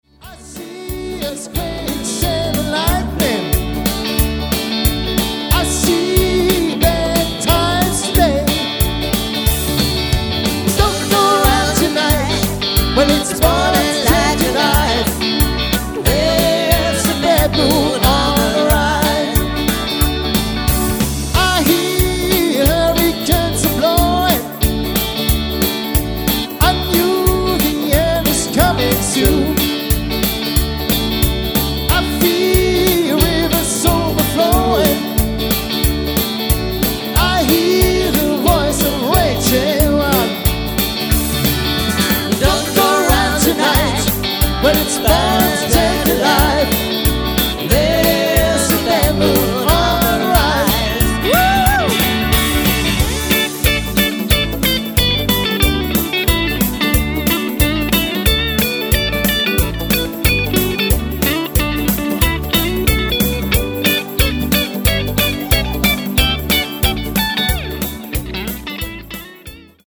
• Allround Partyband